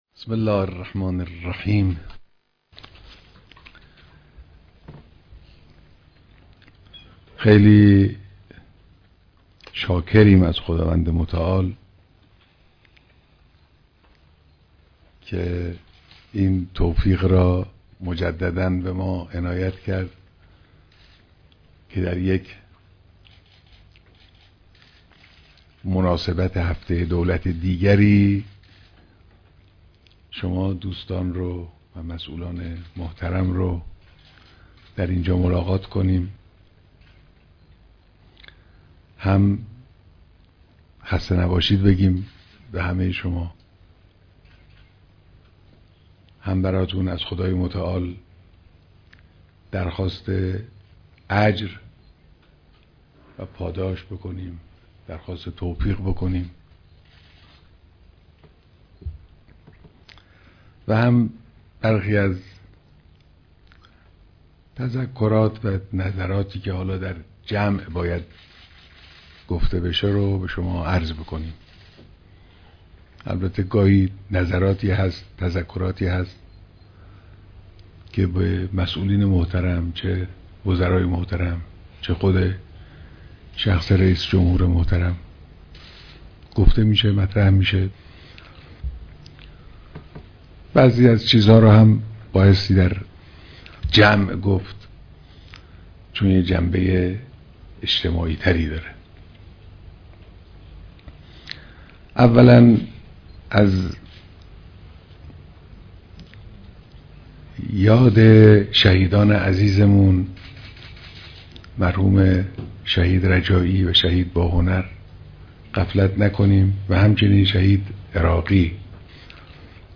دیدار رئیس جمهور و هیأت دولت